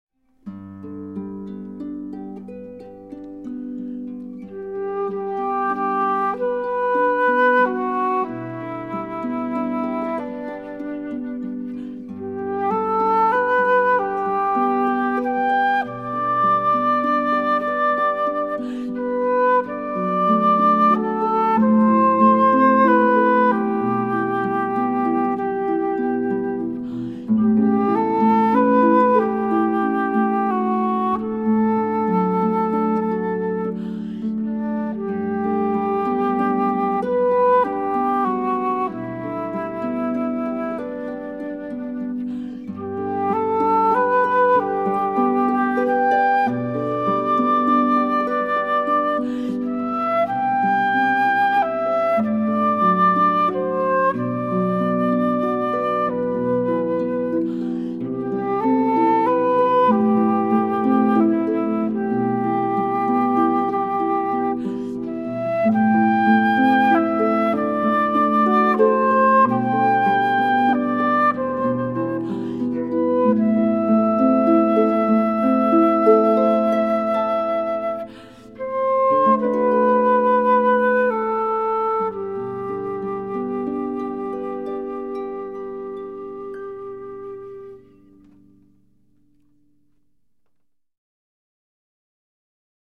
Harpist 1
This group incorporates the angelic tones of the flute and classical harp, mixed together to bring out the best in classical or contemporary selections.
harp
harp3-1.mp3